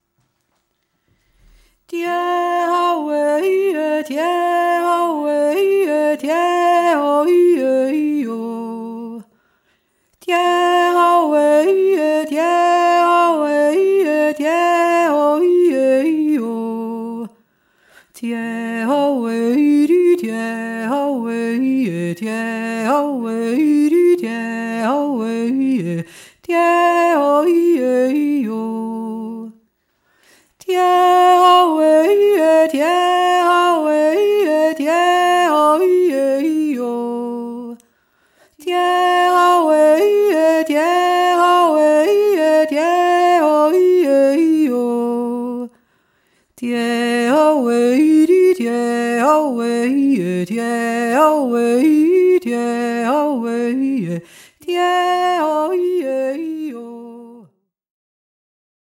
Der Hirtenjodler
hirtenjodelr-1.mp3